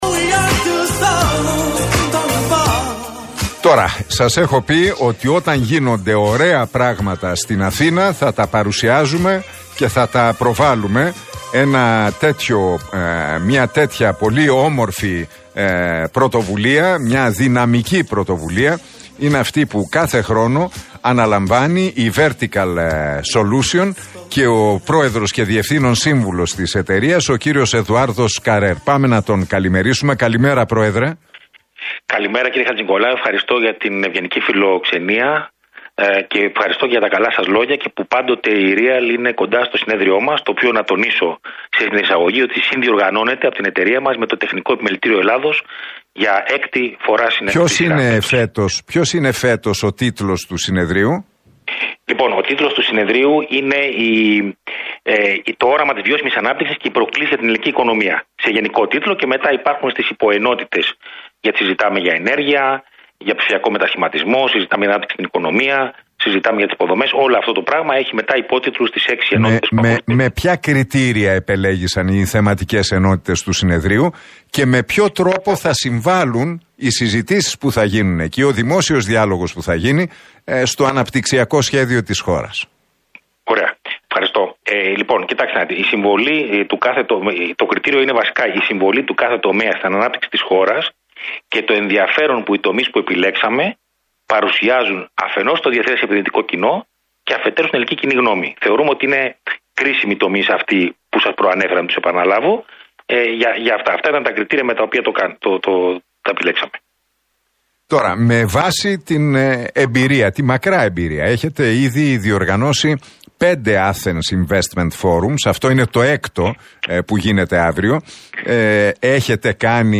δήλωσε μιλώντας στον Realfm 97,8 και στην εκπομπή του Νίκου Χατζηνικολάου ότι «φέτος ασχολούμαστε με την ενέργεια